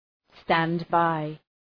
{‘stænd,baı}